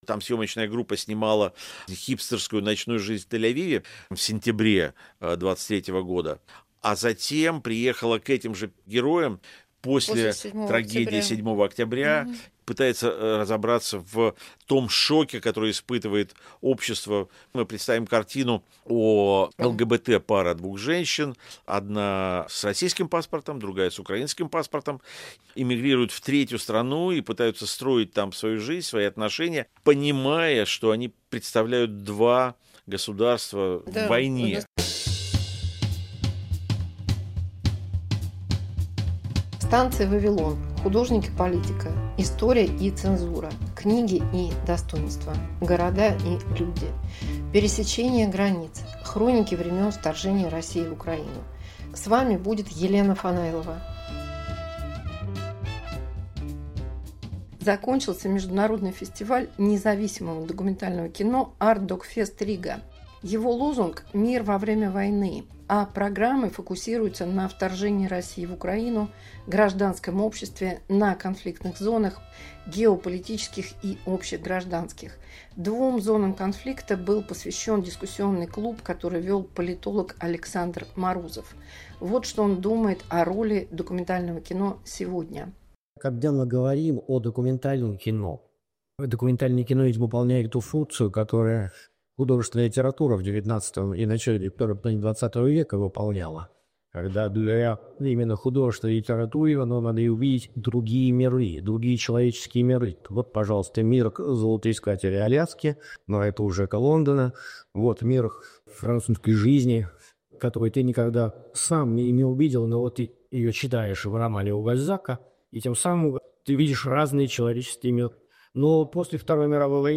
Дискуссия на Artdocfest/Riga 2025